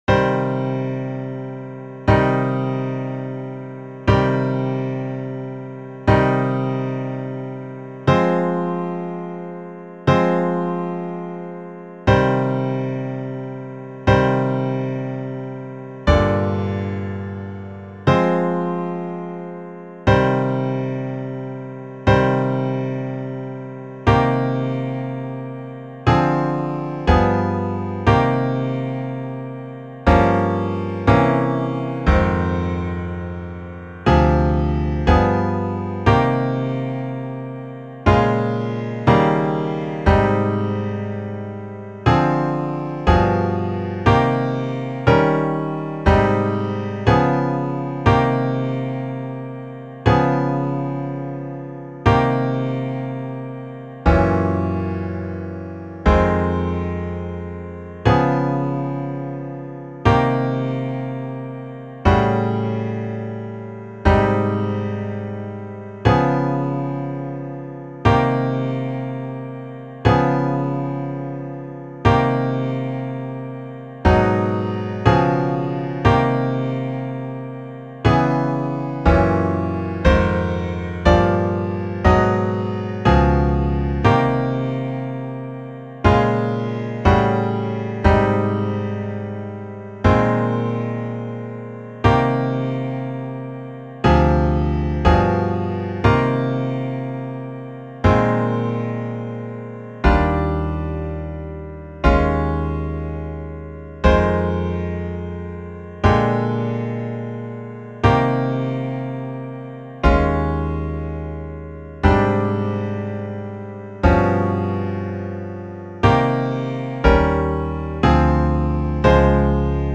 Enjoy some very different chord variations of minor blues.
Topic: Variations on Minor Blues
Minor_Blues_Substitutions.mp3